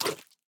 Minecraft Version Minecraft Version 1.21.5 Latest Release | Latest Snapshot 1.21.5 / assets / minecraft / sounds / mob / armadillo / eat1.ogg Compare With Compare With Latest Release | Latest Snapshot
eat1.ogg